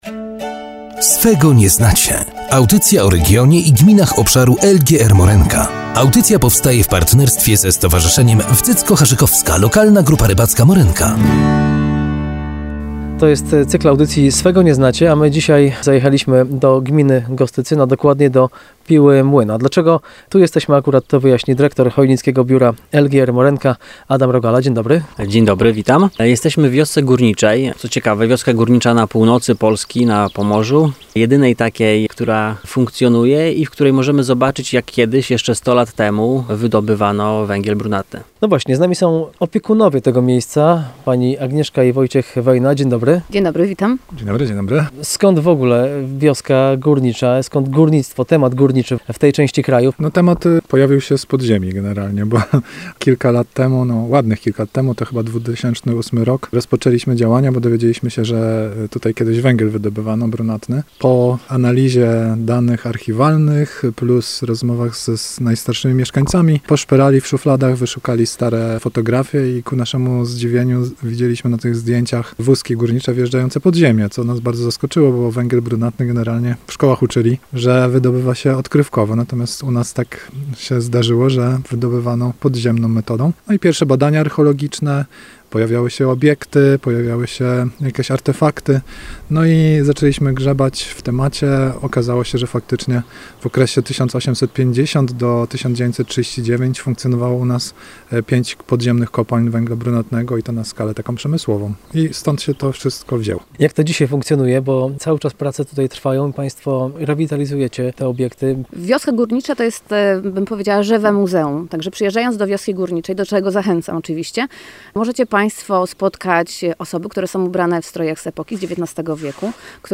Jeden z odcinków poświęcony został Gminie Gostycyn. Reportaż był nagrywany na terenie wioski górniczej w Pile 30 kwietnia 2021 r. Posłuchajcie.